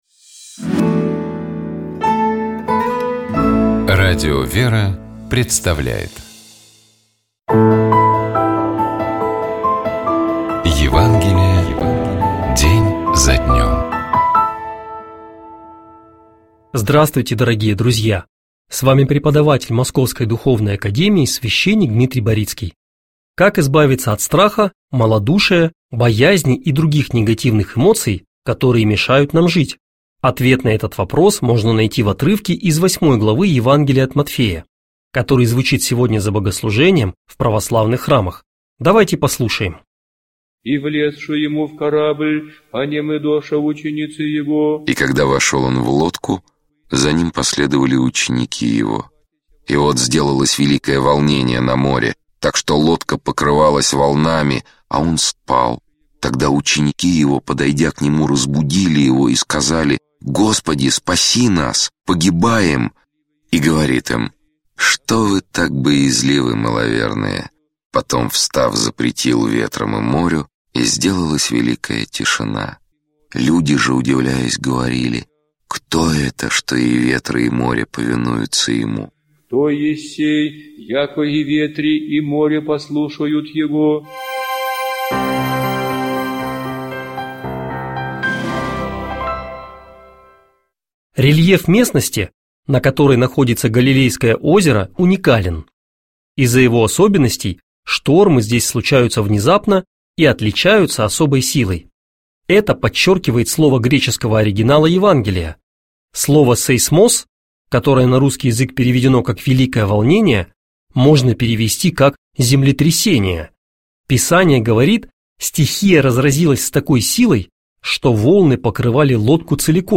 Читает и комментирует